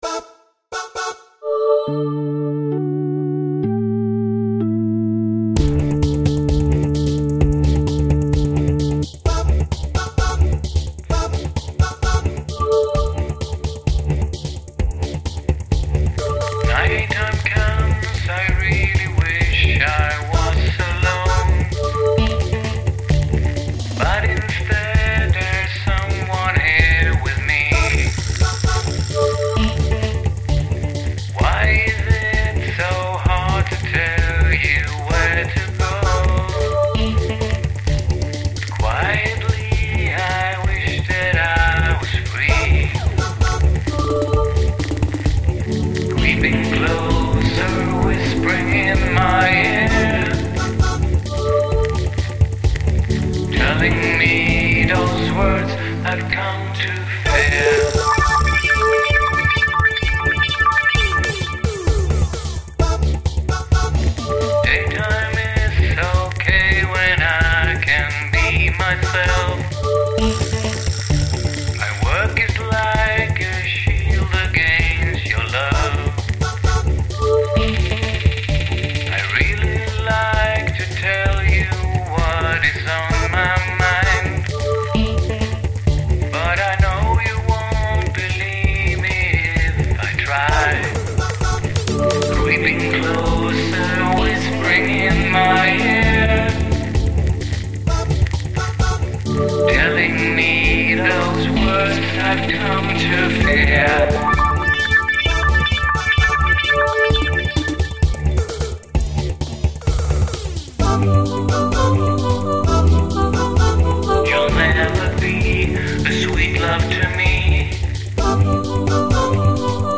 som började med ett studsande basljud